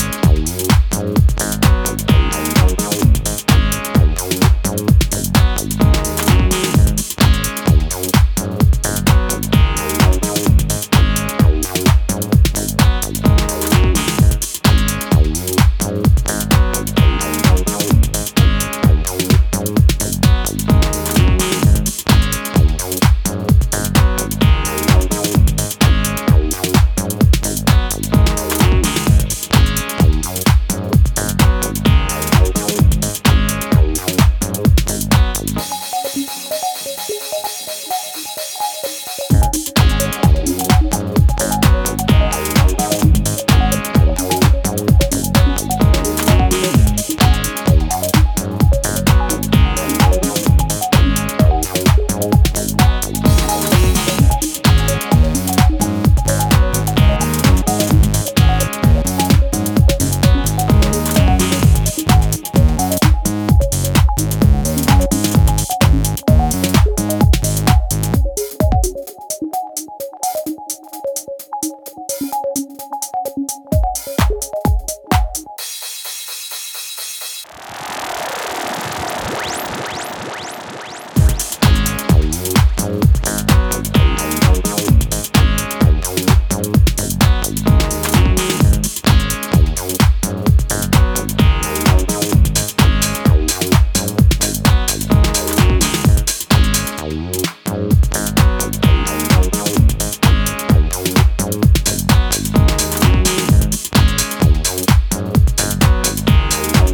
an electronic and freshly optimistic track
an energetic piece that will put dancefloors on fire.